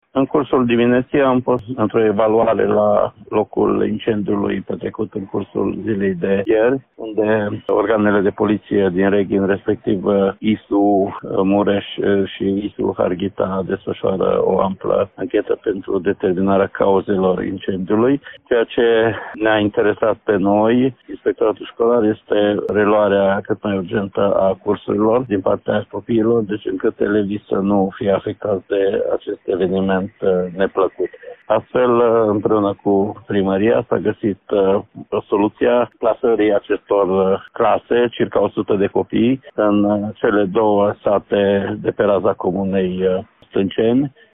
Elevii vor fi transportați la cursuri cu microbuzul școlar, a explicat inspectorul școlar general al județului Mureș, Ștefan Someșan: